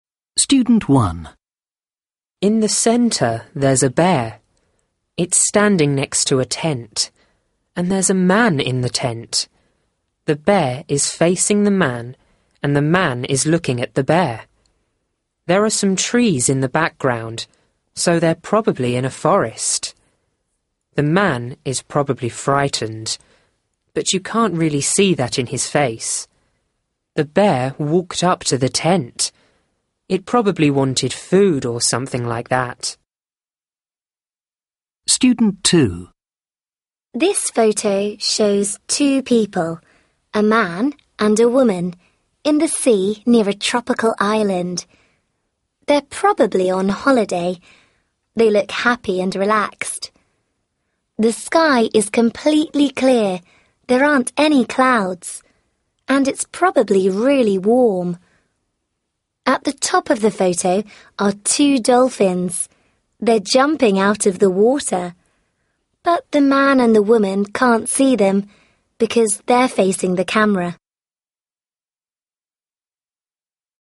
2. Listen to two students describing photos A and B above.